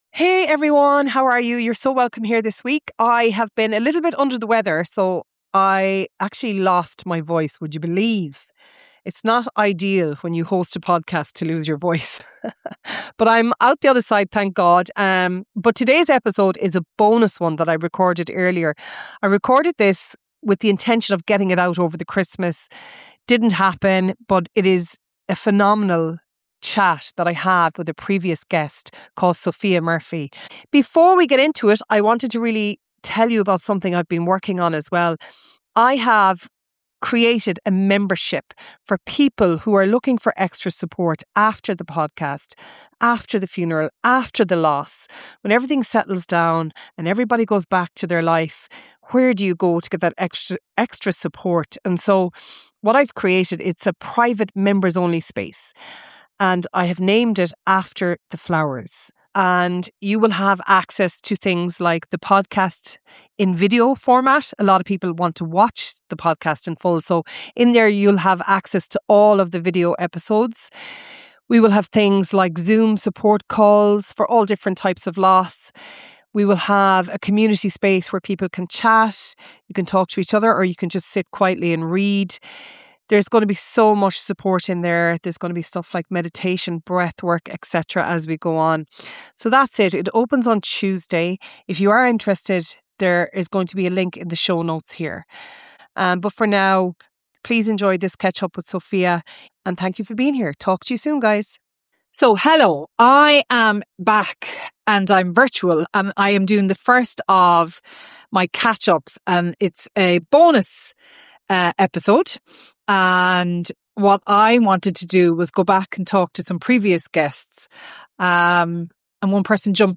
This is an honest, thoughtful conversation about trauma, survival, and the different paths people take toward healing.